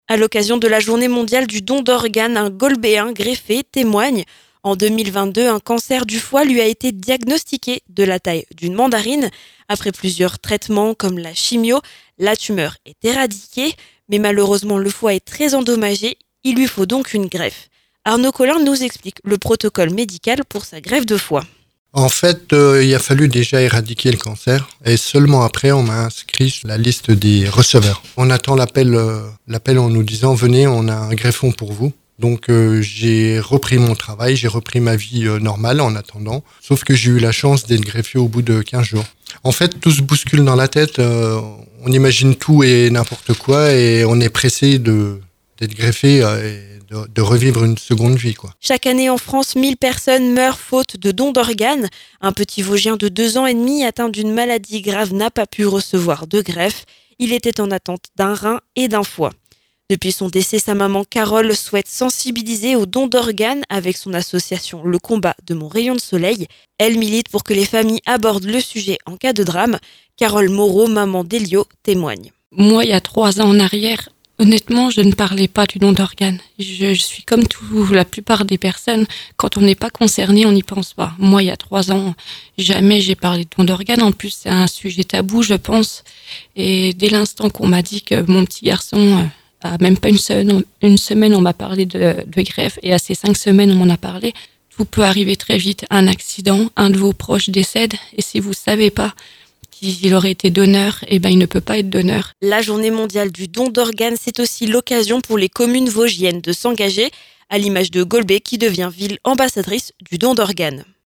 Le don d'organes, deux Vosgiens partagent leur expérience.